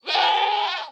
Minecraft Version Minecraft Version snapshot Latest Release | Latest Snapshot snapshot / assets / minecraft / sounds / mob / goat / scream8.ogg Compare With Compare With Latest Release | Latest Snapshot
scream8.ogg